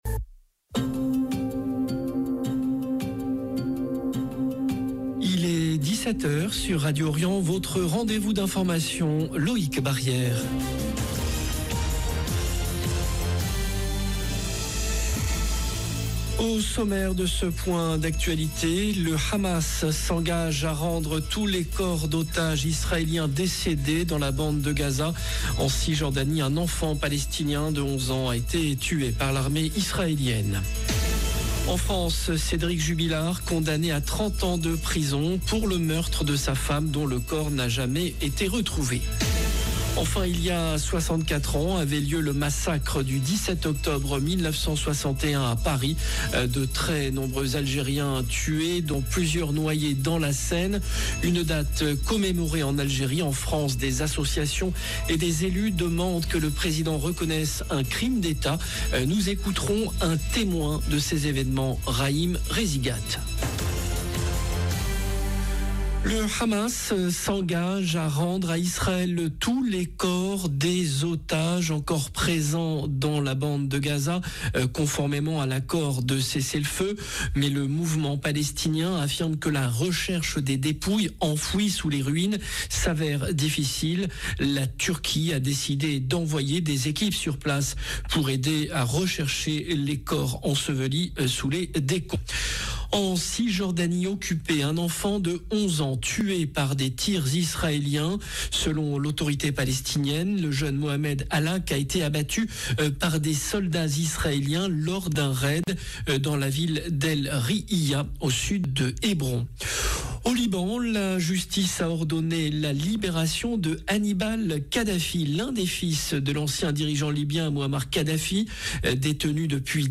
JOURNAL DU 17H